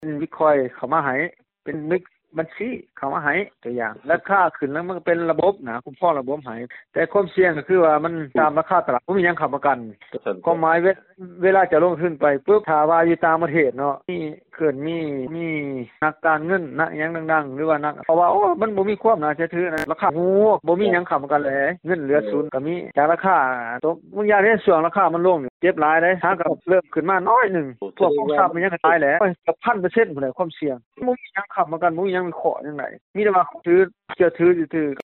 ເຊີນຟັງ ນັກທຸລະກິດ